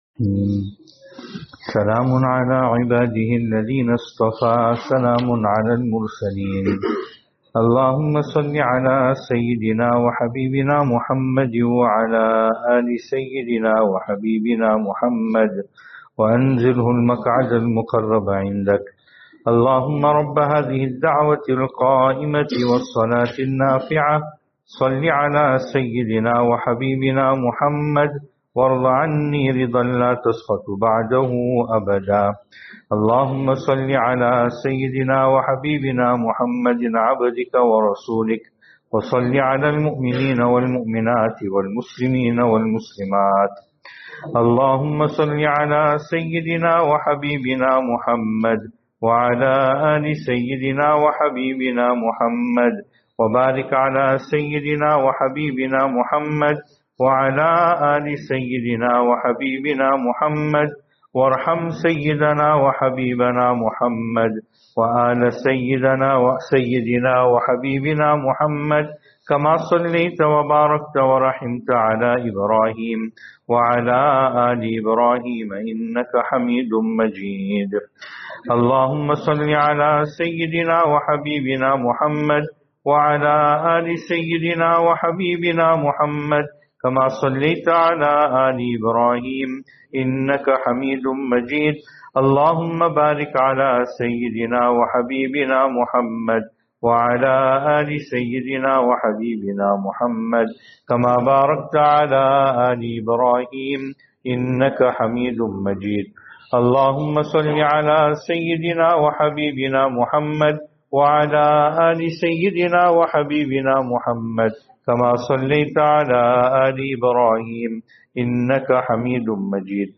Venue: Albert Falls , Madressa Isha'atul Haq Series: Islahi Majlis